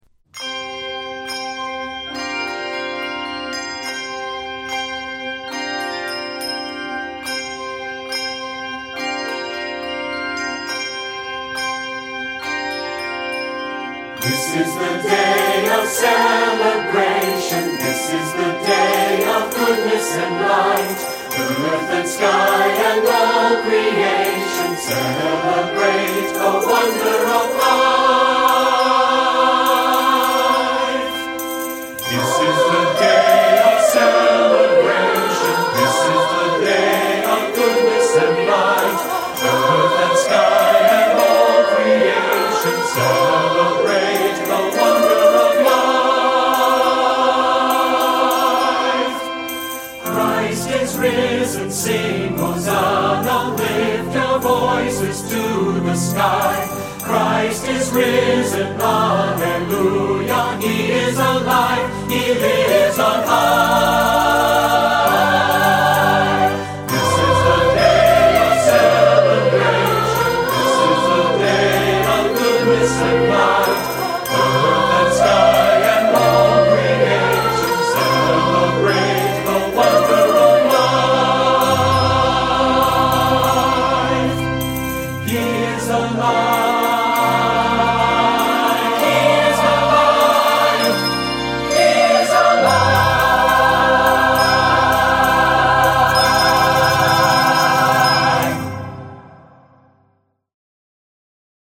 Key of C major; 51 measures.